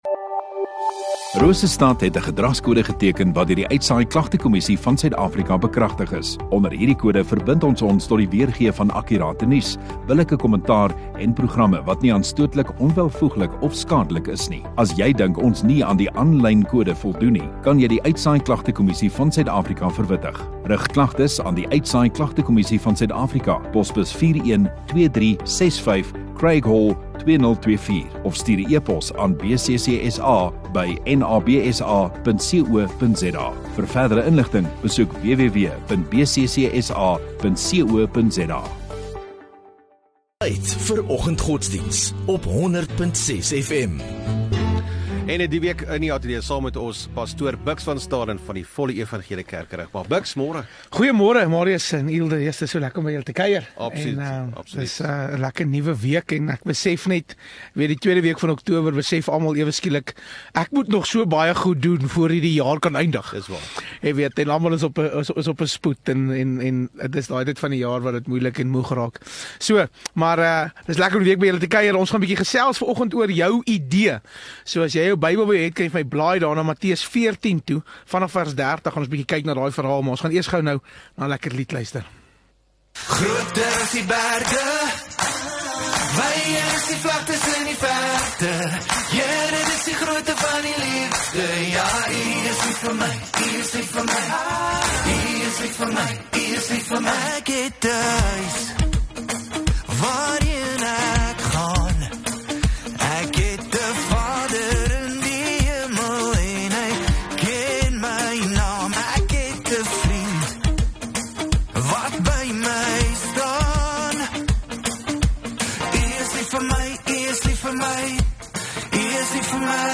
7 Oct Maandag Oggenddiens